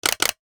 NOTIFICATION_Click_12_mono.wav